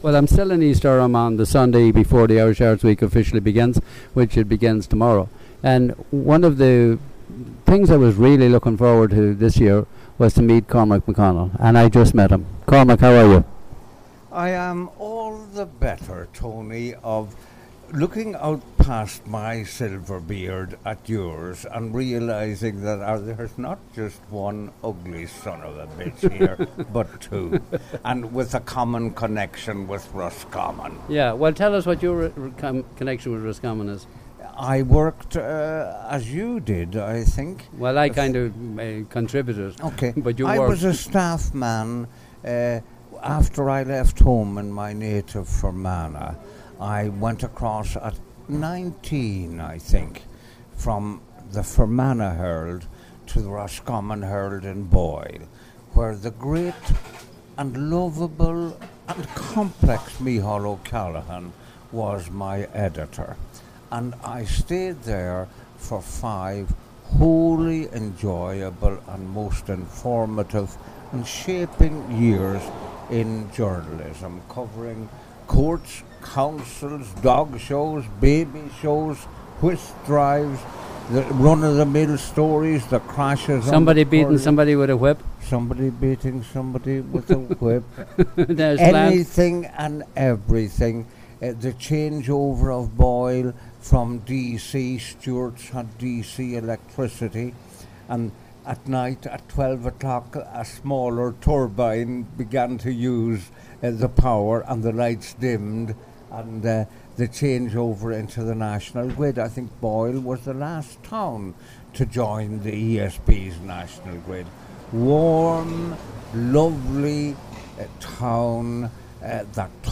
interview from Irish Arts Week 2014